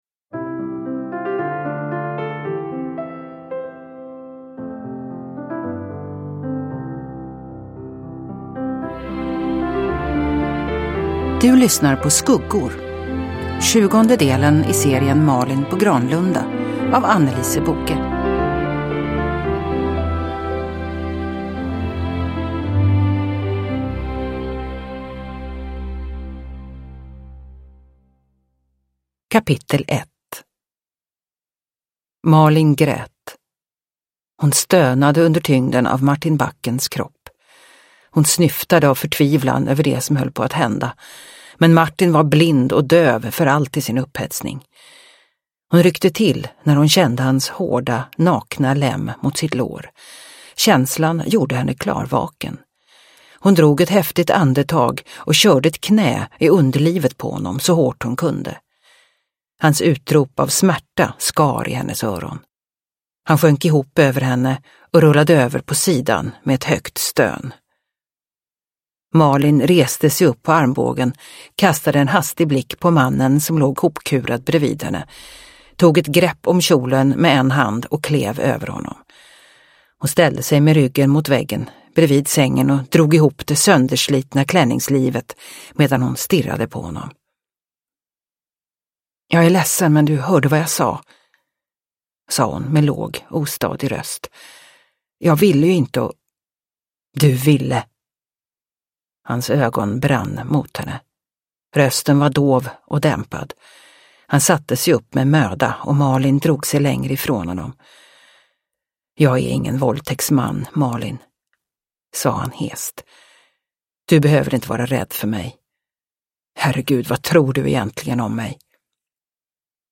Skuggor – Ljudbok – Laddas ner